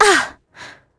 Isaiah-Vox_Damage.wav